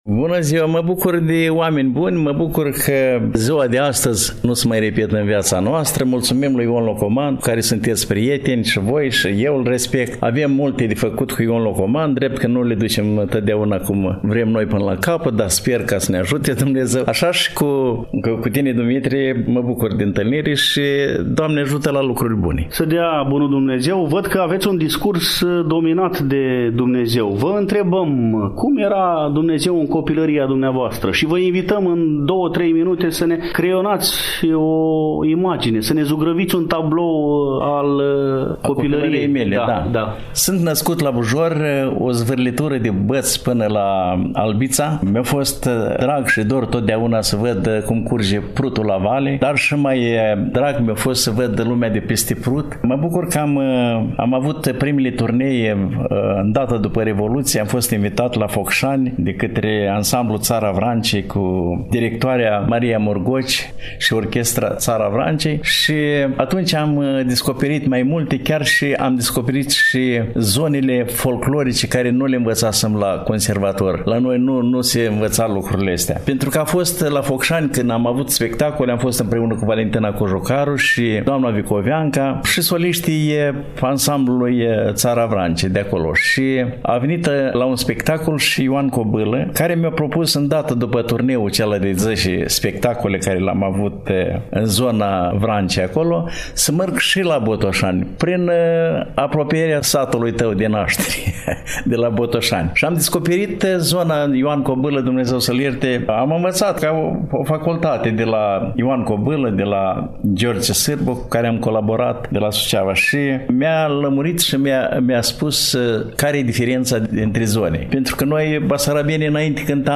1_Dialog-Interpret-de-Muzica-Populara-Mihai-Ciobanu-6-01.mp3